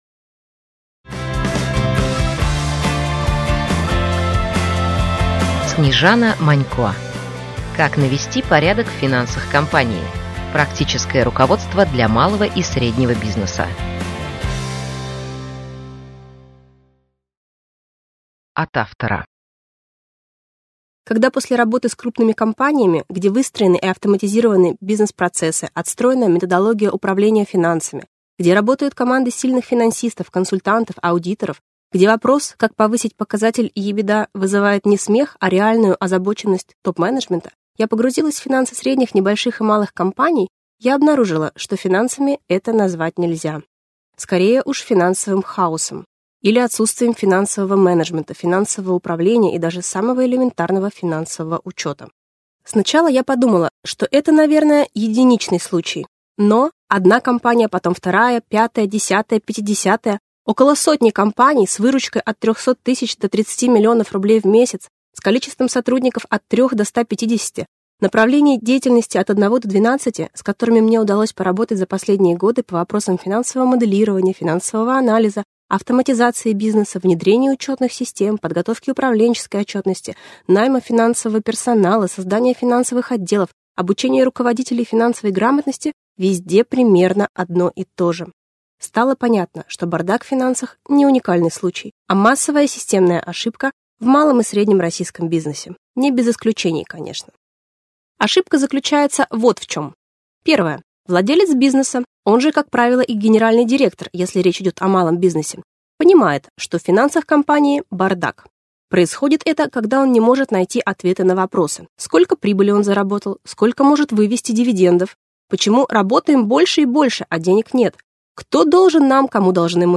Аудиокнига Как навести порядок в финансах компании: Практическое руководство для малого и среднего бизнеса | Библиотека аудиокниг